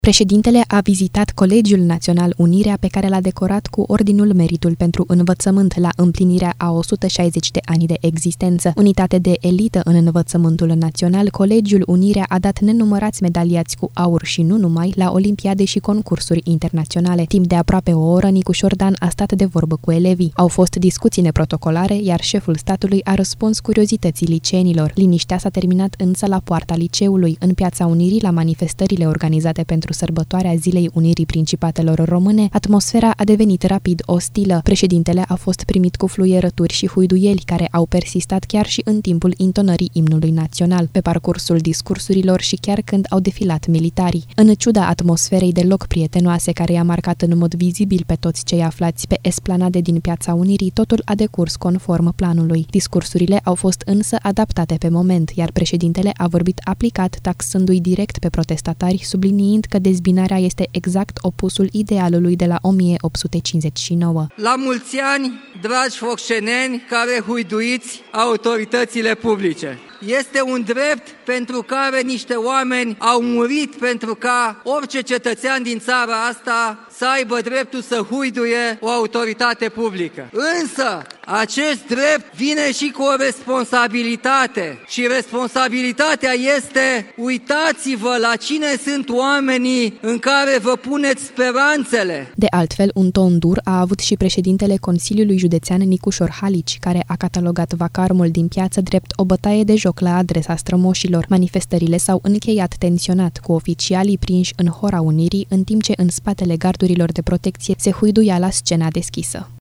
Șeful statului a fost întâmpinat cu onoruri academice la Colegiul „Unirea”, unde a fost dimineață, și un cor de huiduieli în centrul orașului.
Președintele a fost primit cu fluierături și huiduieli, care au persistat chiar și în timpul intonării Imnului Național, pe parcursul discursurilor și chiar când au defilat militarii.